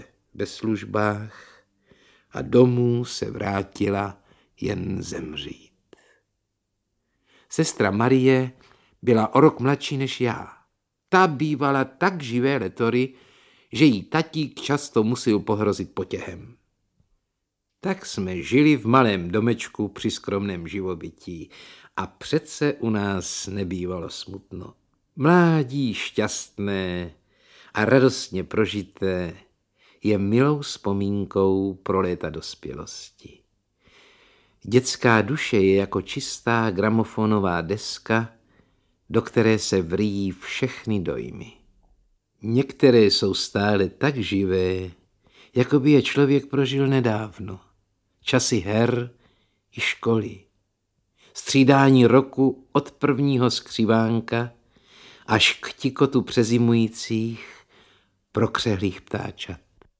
Audiobook
Read: Martin Růžek